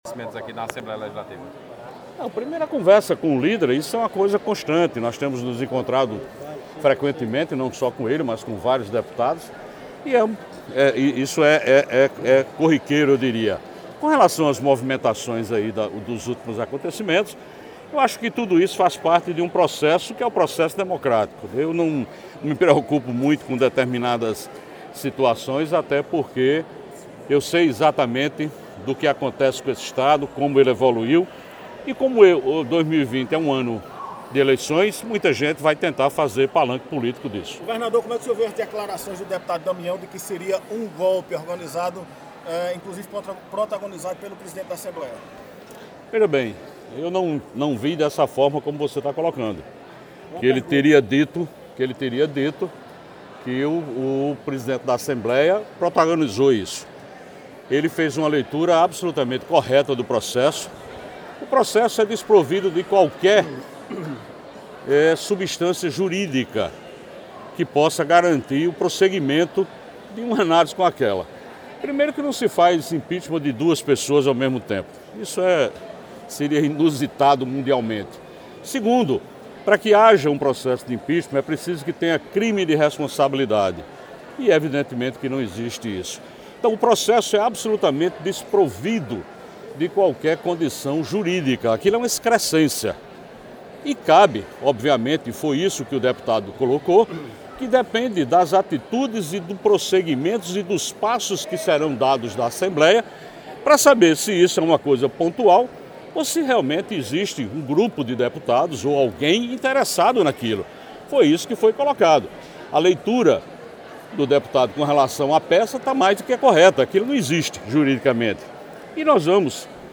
O governado da Paraíba, João Azevêdo (Cidadania), durante solenidade de início do ano letivo estadual, comentou sobre as declarações do deputado federal Damião Feliciano (PDT), que disse a existência de um suposto esquema entre os deputados estaduais e a participação do presidente da Assembleia, Adriano Galdino, para colocar em andamento o pedido de impeachment protocolado pela oposição na Casa.
Ouça abaixo a fala do governador João Azevêdo que inicia falando da reunião que teve com o seu líder na Assembleia, deputado Ricardo Barbosa.